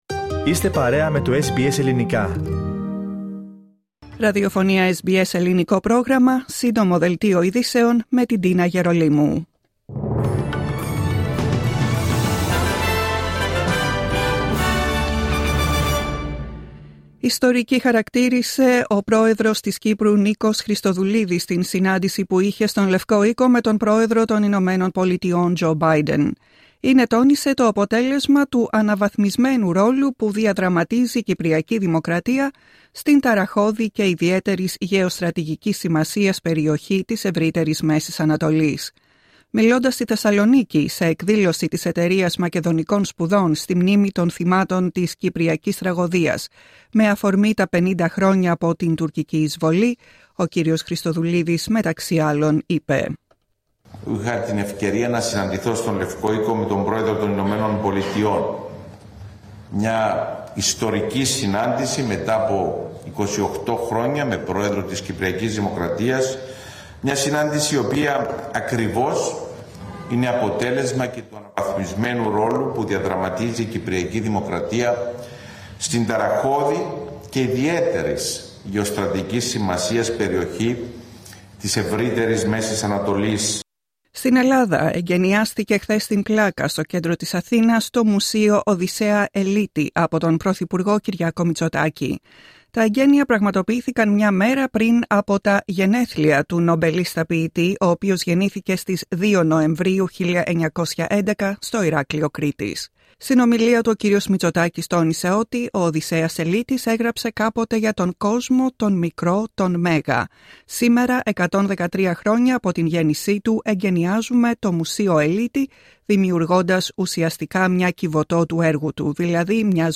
Δελτίο ειδήσεων Σάββατο 2 Νοεμβρίου 2024
Συνοπτικό δελτίο ειδήσεων απ΄το Ελληνικό Πρόγραμμα της SBS.